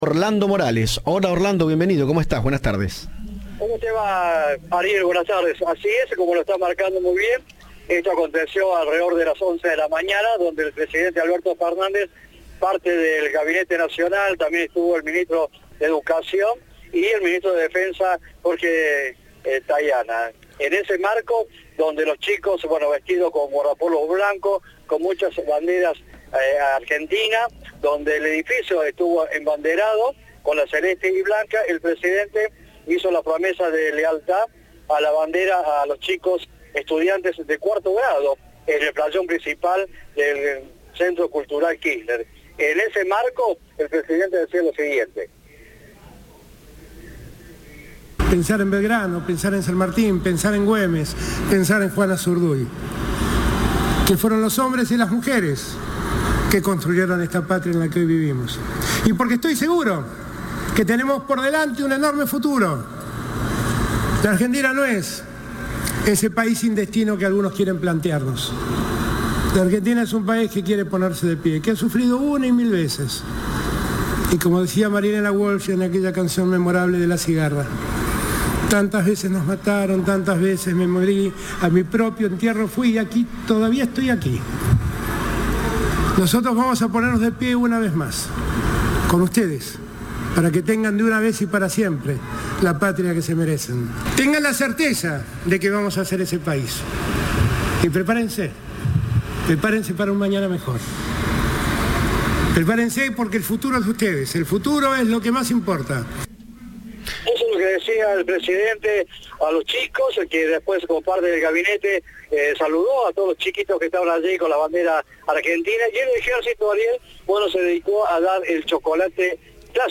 El presidente Alberto Fernández encabeza el acto por el día de la Bandera en el CCK
Audio. Alberto Fernández, en el acto del 20 de junio: "Argentina no es un país sin destino"